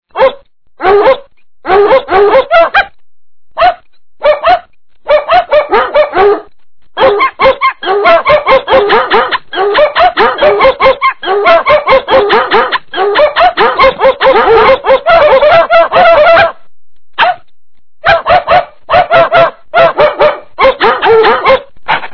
搞笑狗叫声 特效音效铃声二维码下载
搞笑狗叫声 特效音效
gao_xiao_gou_jiao_sheng-te_xiao_yin_xiao331.mp3